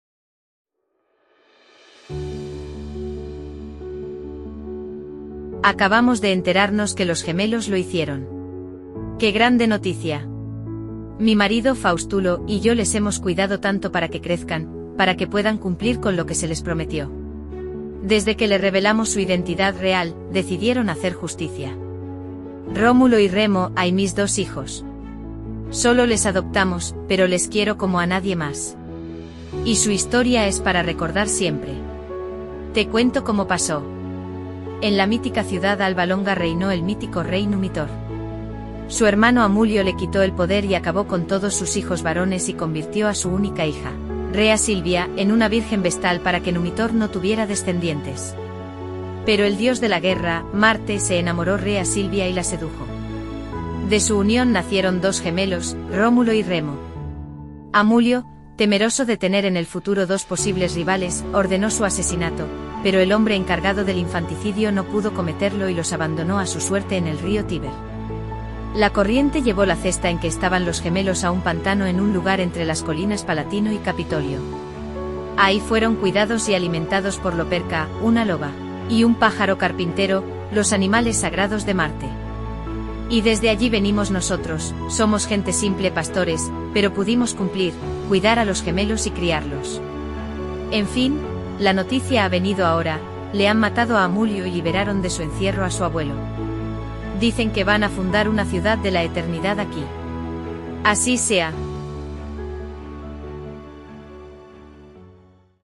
La mujer de Fáustulo habla acerca de la historia de Rómulo y Remo